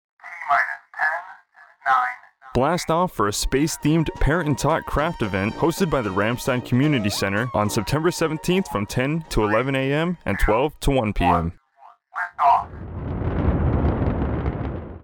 A 15-second radio spot for the Parent and Tots space-themed craft event that will air on AFN Kaiserslautern from July 18, 2024, to Sept. 16, 2024.